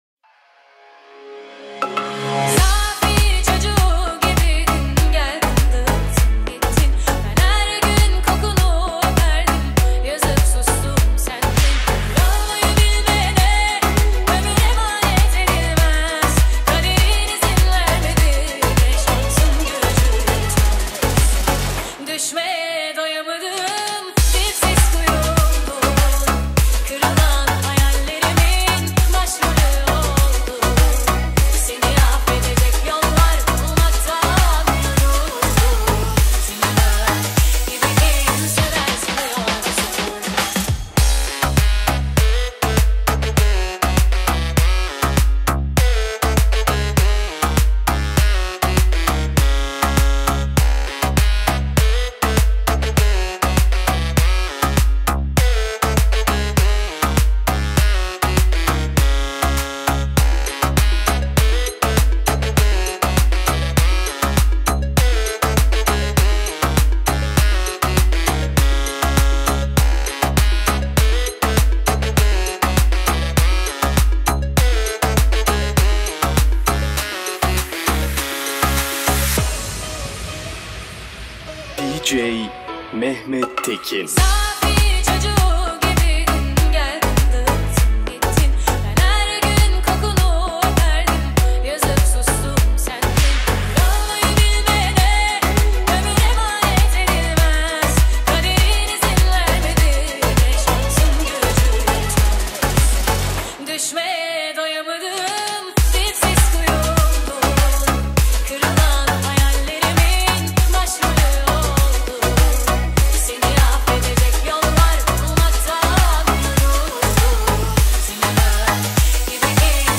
яркая поп-композиция